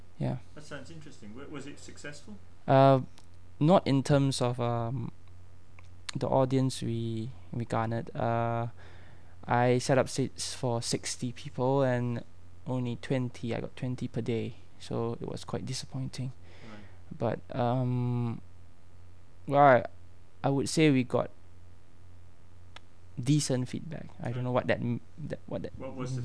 The NIE Corpus of Spoken Singapore English